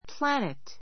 planet 中 A2 plǽnit プ ら ネ ト 名詞 惑星 わくせい , 遊星 ⦣ 地球のように, 太陽の周囲を回転している星.